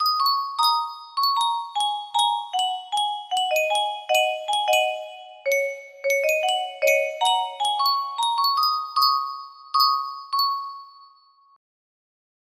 mulki music box melody
Grand Illusions 30 (F scale)